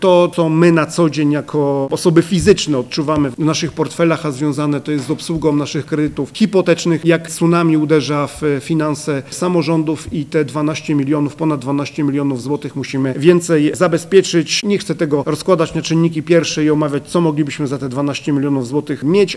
Wszystko to związane jest z rosnącymi stopami procentowymi, mówi prezydent Radomia Radosław Witkowski: